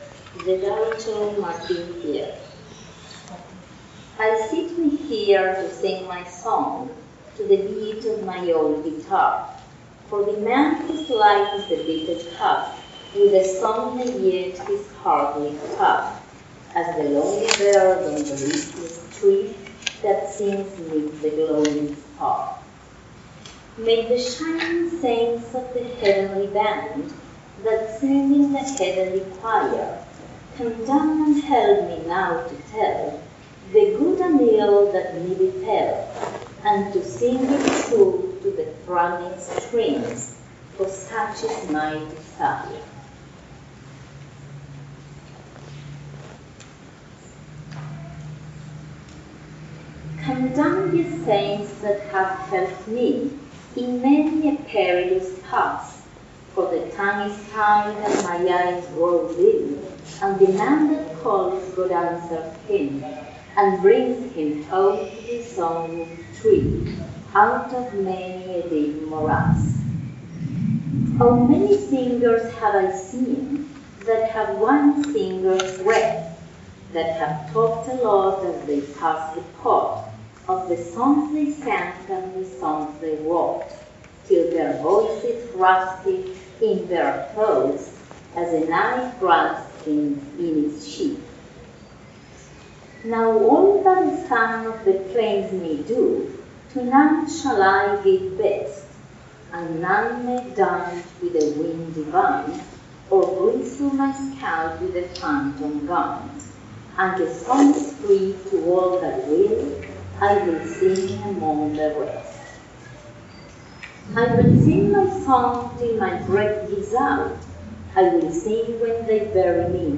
Evento: Jornadas Políglotas Martín Fierro (City Bell, 1° de diciembre de 2023)